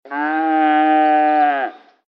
ACTIVITAT 8. QUIN ANIMAL FA AQUEST SOROLL?
vaca.mp3